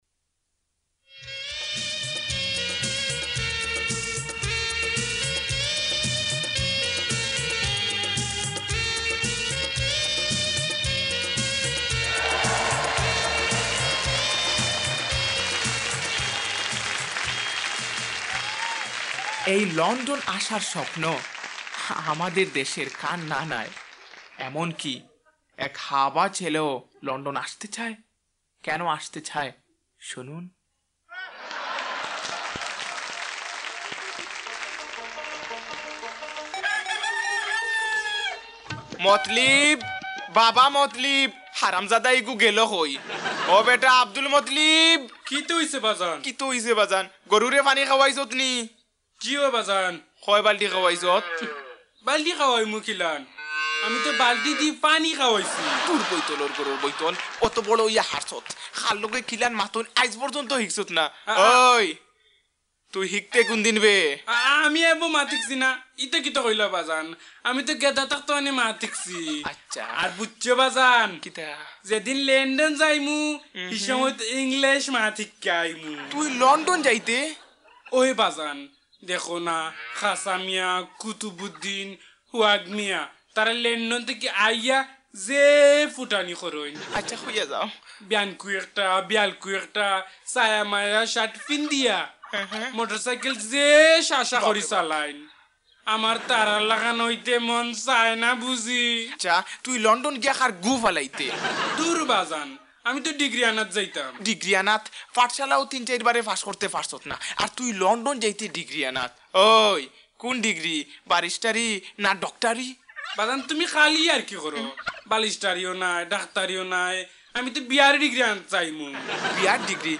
Dunia Part 2 – Comedy